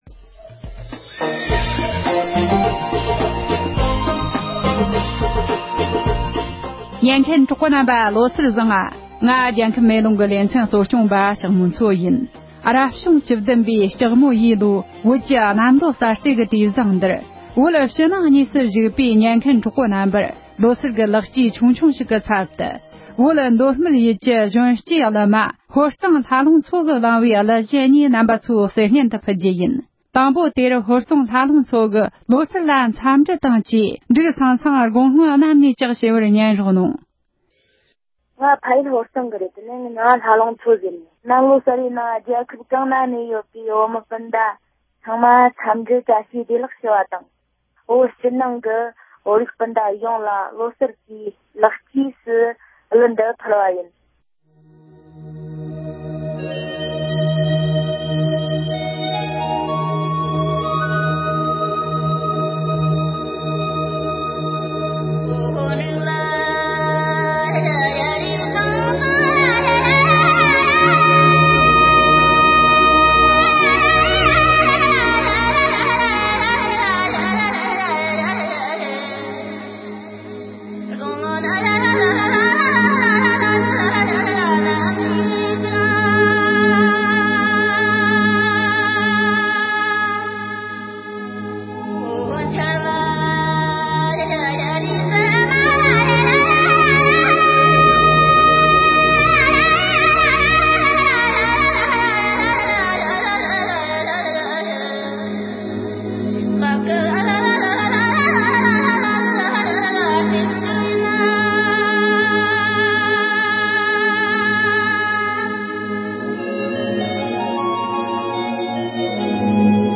བོད་ནང་གི་གཞོན་སྐྱེས་གླུ་མ་